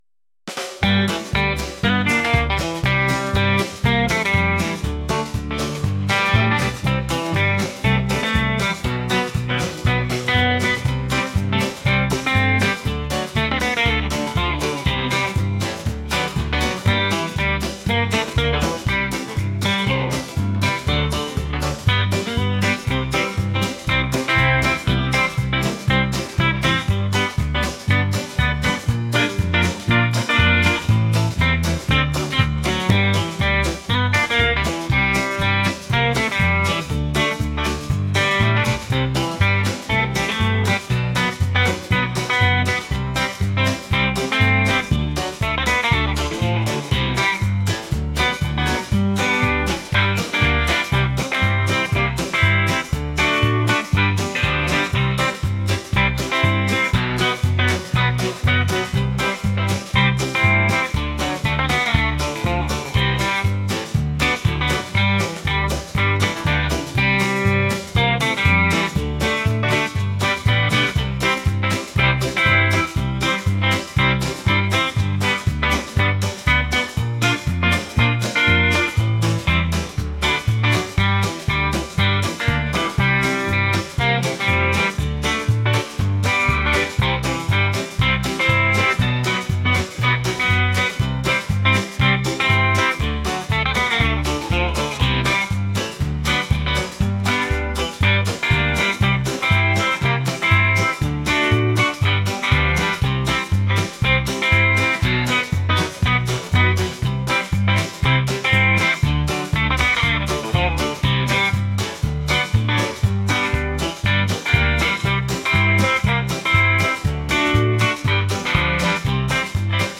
country | lively